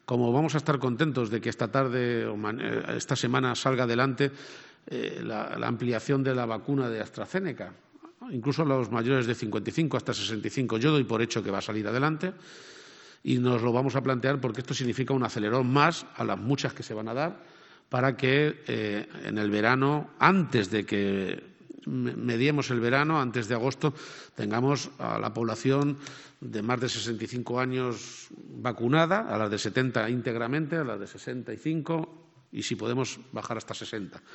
«Vamos a dar un acelerón para que antes de que mediemos el verano, antes de agosto, esté vacunada toda la población de más de 65 años y, si podemos, también a los mayores de 60», ha explicado el presidente en un acto en Talavera, donde se ha firmado un acuerdo para construir un nuevo centro de atención de enfermos de Alzheimer.